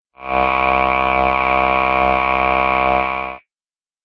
Download Robot Copy sound effect for free.
Robot Copy